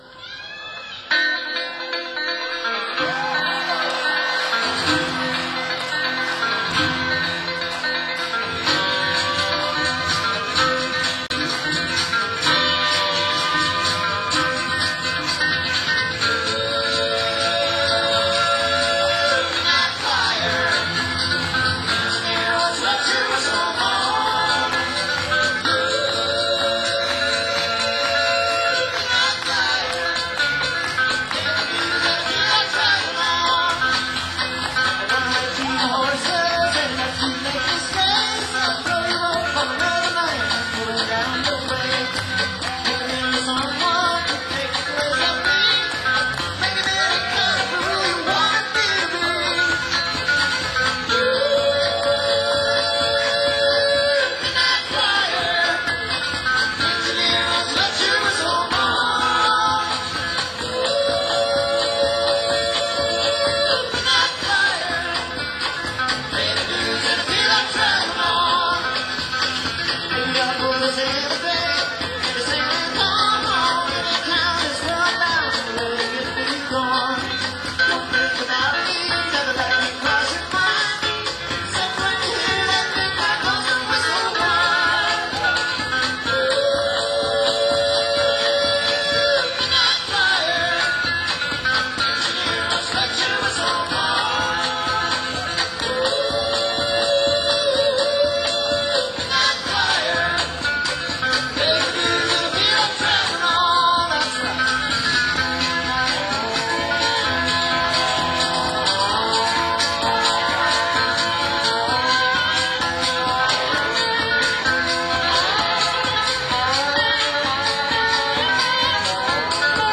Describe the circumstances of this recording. Civic Center, Springfield, MA Audience Recording